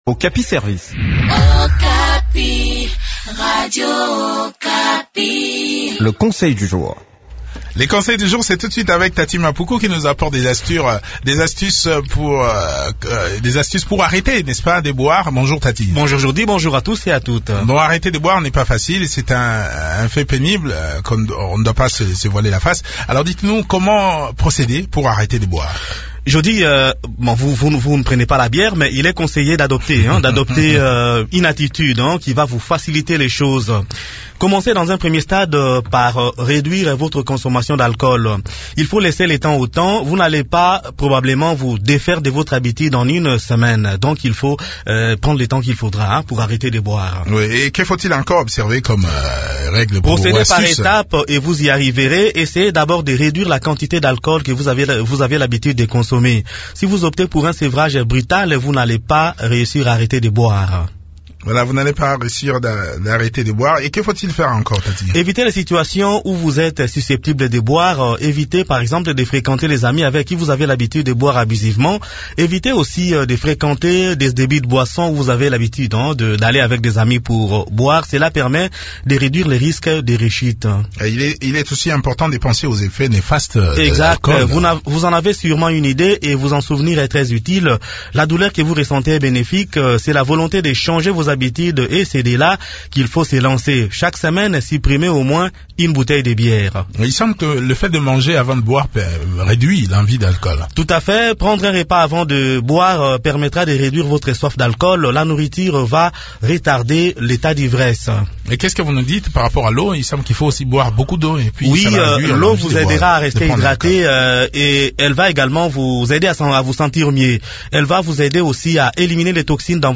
Découvrez des astuces qui peuvent vous aider à arrêter de boire dans cette chronique